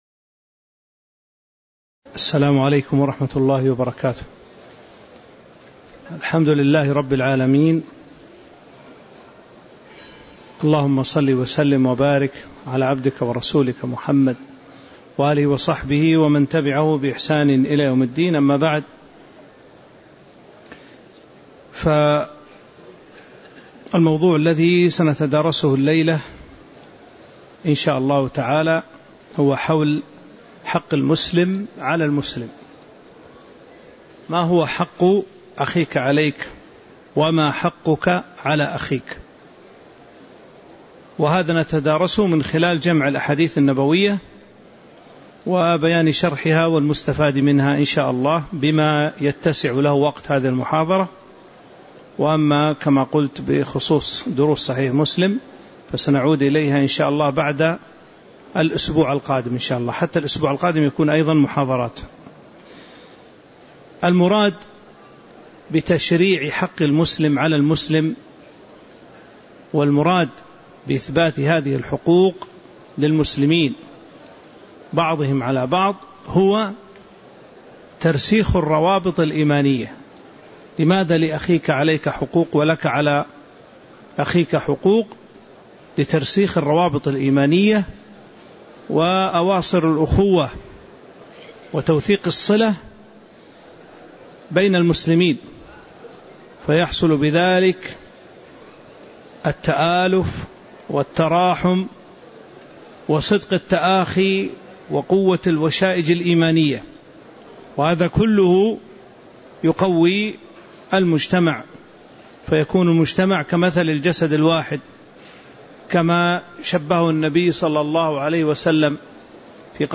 تاريخ النشر ٢٤ جمادى الآخرة ١٤٤٦ هـ المكان: المسجد النبوي الشيخ